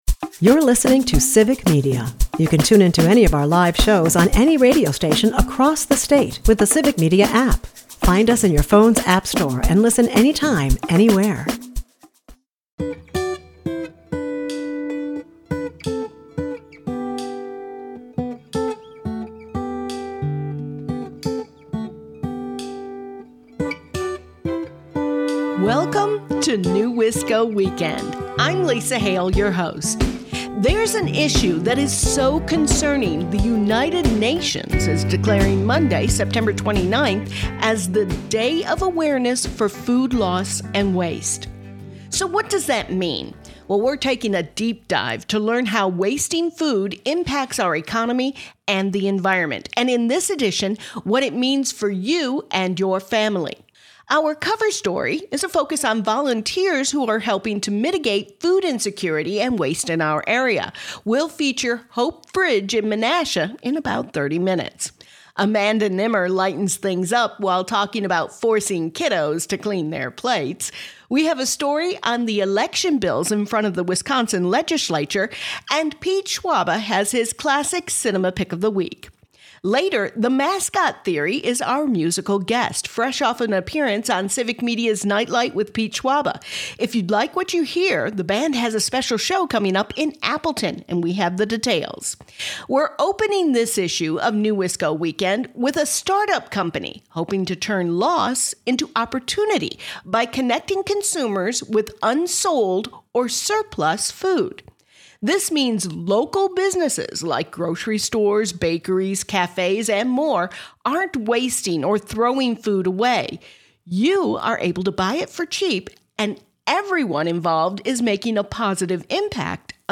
Rep. Scott Krug and Rep. Lee Snodgrass at joint conference
NEWisco Weekend is a part of the Civic Media radio network and airs Saturdays at 8 am and Sundays at 11 am on 97.9 WGBW and 98.3 and 96.5 WISS.